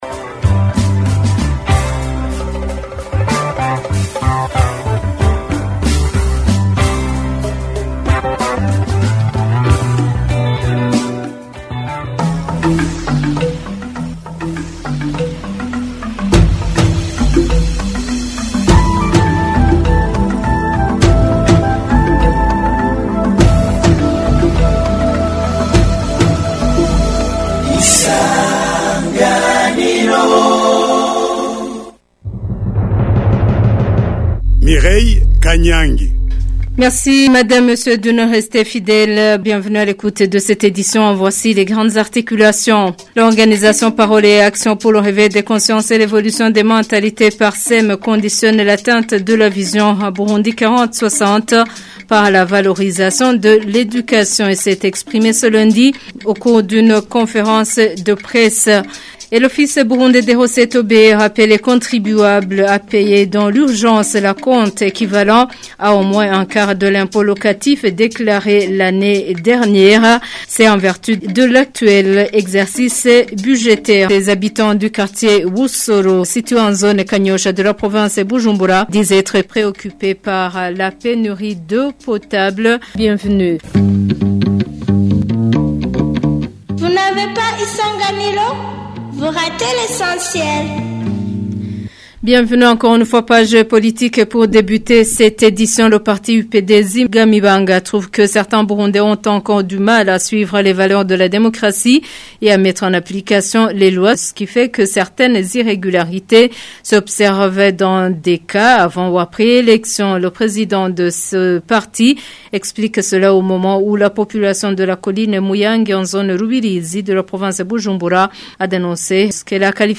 Journal du 22 septembre 2025